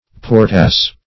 Search Result for " portass" : The Collaborative International Dictionary of English v.0.48: Portass \Por"tass\, n. [OF. porte-hors a kind of prayer book, so called from being portable; cf. LL. portiforium.]